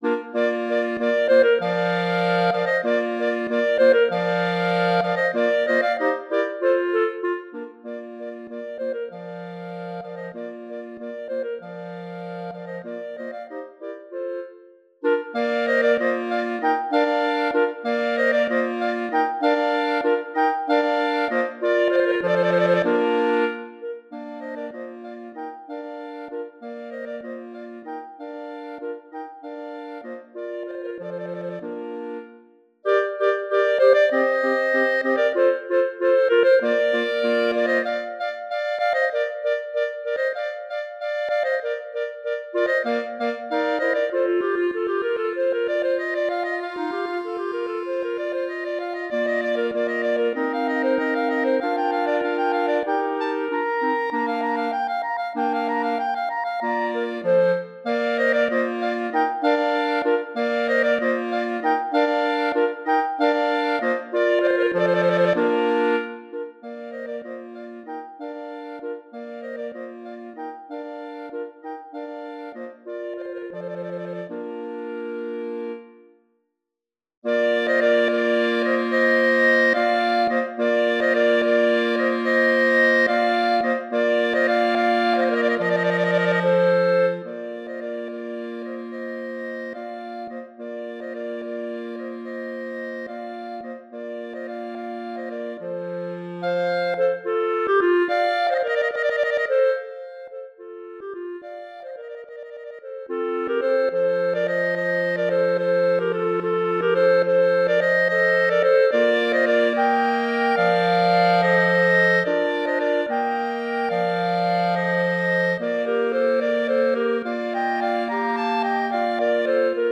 Voicing: Bb Clarinet Quartet